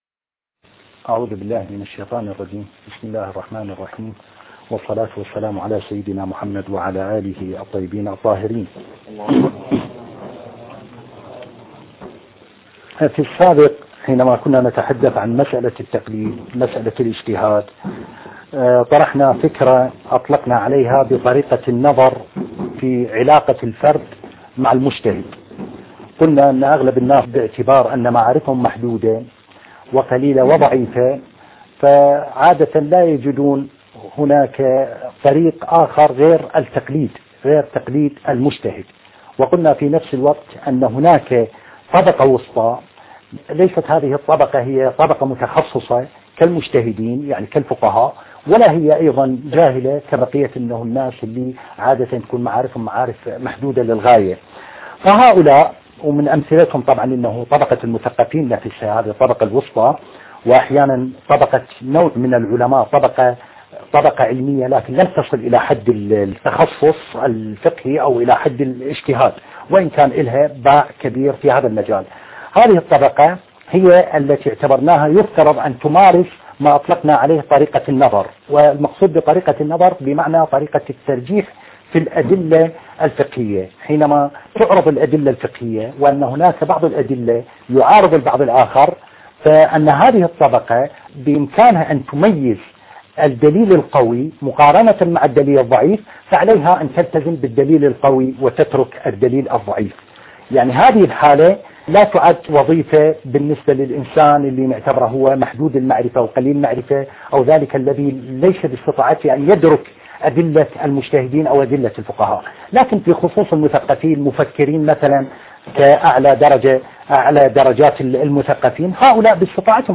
الدرس التاسع :خلاف المثقفين الرواد مع الفقهاء